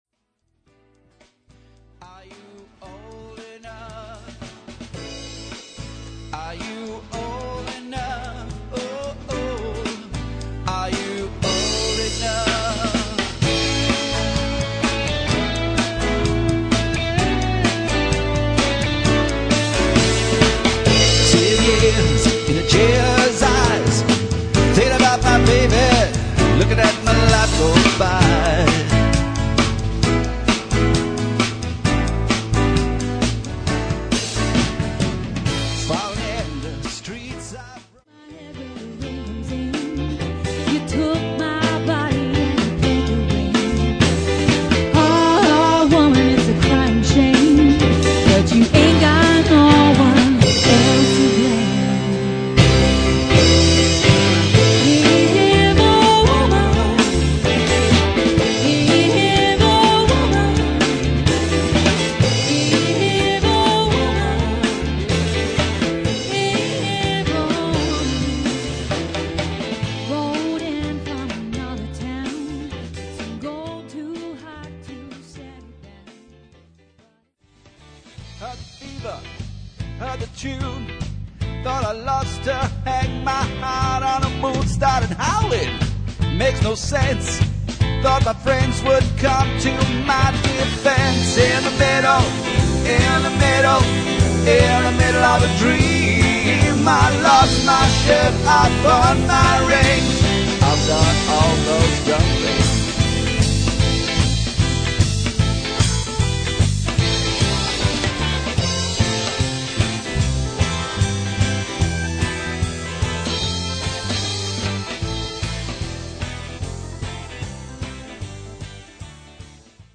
versatile five piece band
charming, attractive, versatile vocalist
Keyboards
on lead guitar vocals and backing vocals
Drums/Percussion
Bass Guitar/Vocals
strong vocals and driving bass